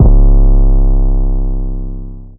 DEEDOTWILL 808 20.wav